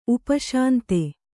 ♪ upa śante